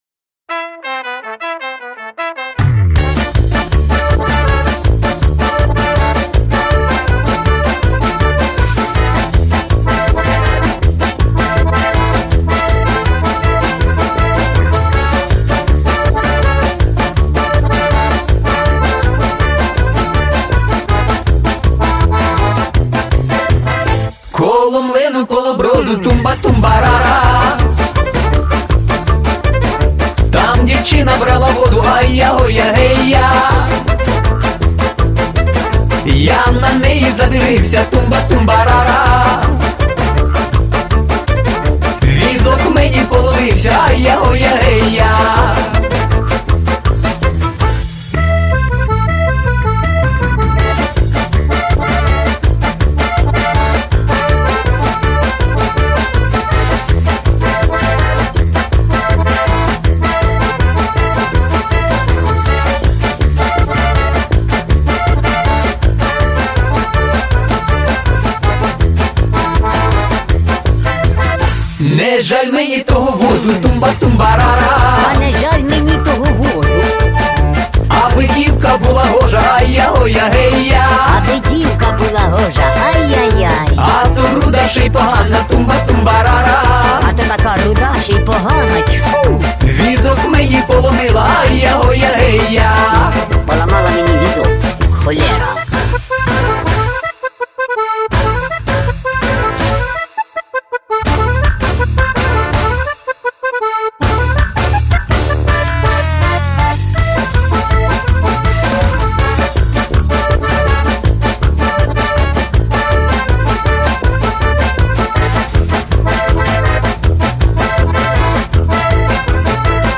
украинскую народную песню
Конечно, это сугубо авторская обработка…